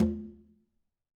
2-quinto.wav